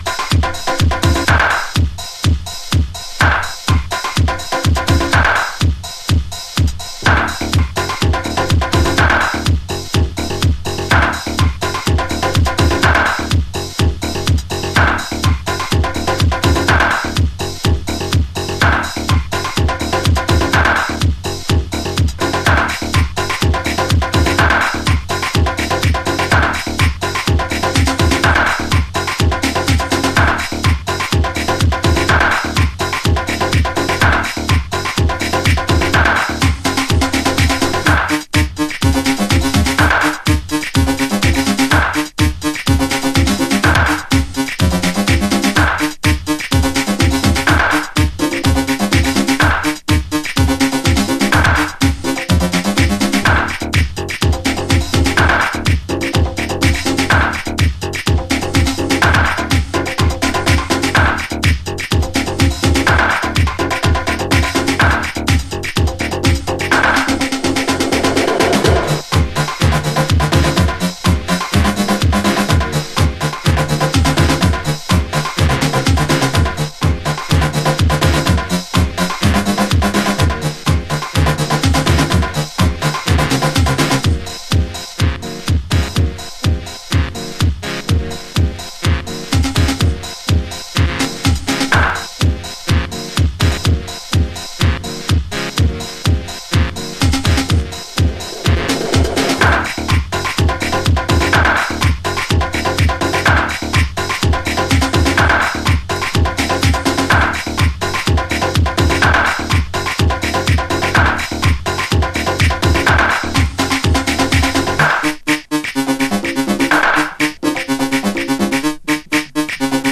TOP > Chicago Oldschool
シンセもビートも暴れ放題、ジャンクなマッドネスを秘めたジャックハウス。